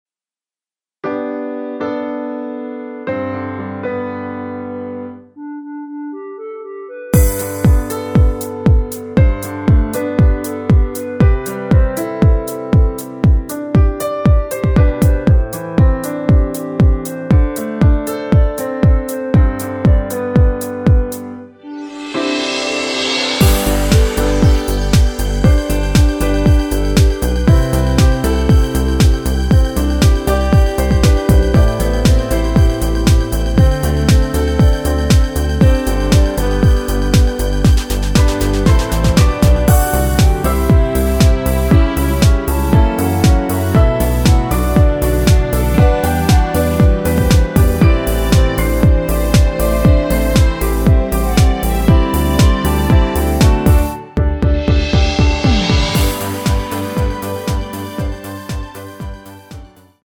전주가 없는 곡이라 2마디 전주 만들어 놓았습니다.
(여자키) 멜로디 포함된 MR 입니다.(미리듣기 참조)
앞부분30초, 뒷부분30초씩 편집해서 올려 드리고 있습니다.
(멜로디 MR)은 가이드 멜로디가 포함된 MR 입니다.